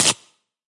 来自我的卧室的声音" 磁带延伸小4 ( 冻结 )
描述：在Ableton中录制并略微修改的声音